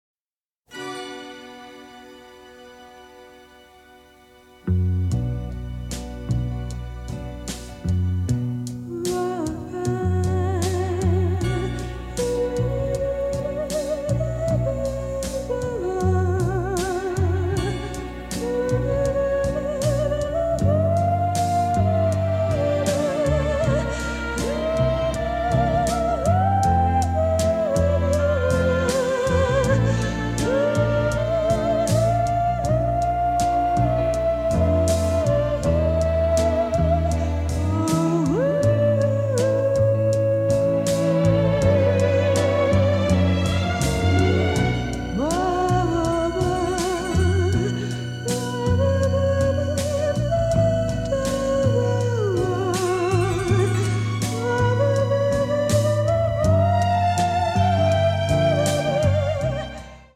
a wonderful romantic horror score
in beautiful pristine stereo